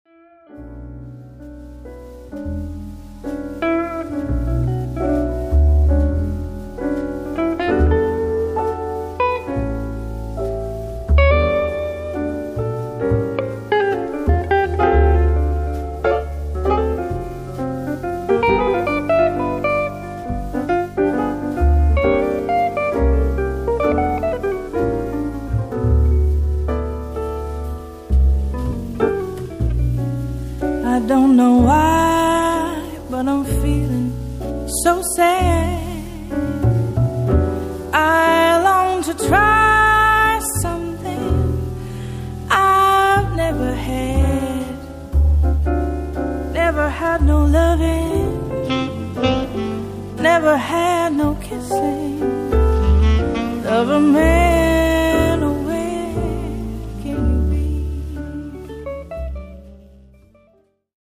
Jazz meets acoustic pop